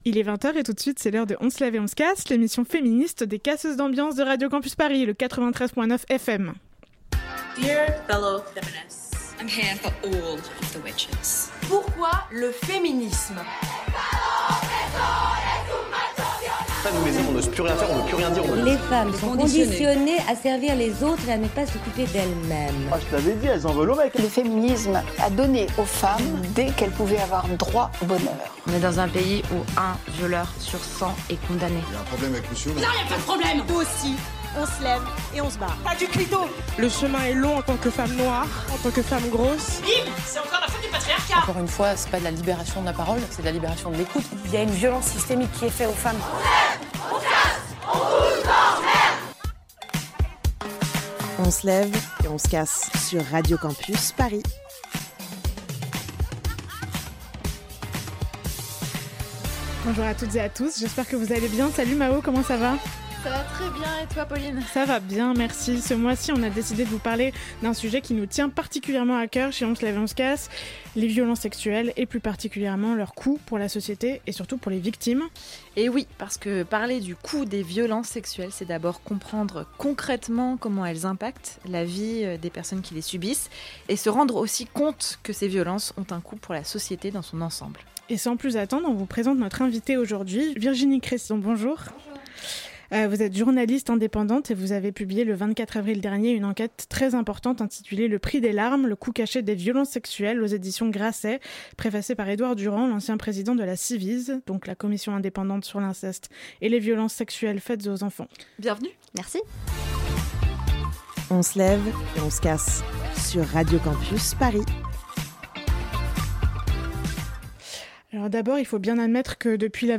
Notre invitée